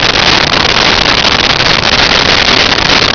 Sfx Amb Trams Loop
sfx_amb_trams_loop.wav